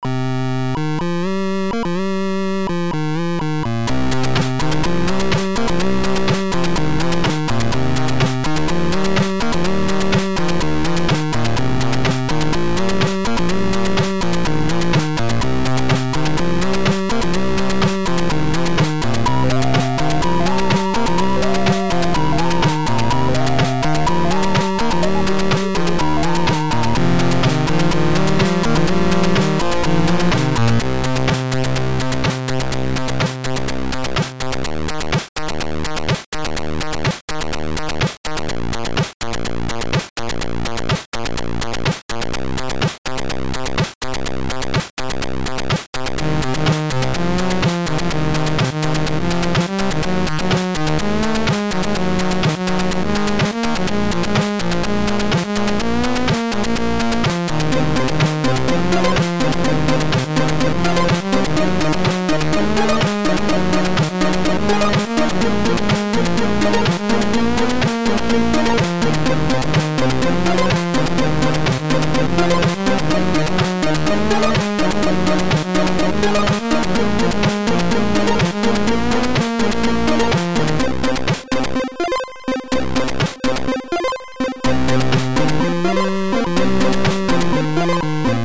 Type AHX v2 Tracker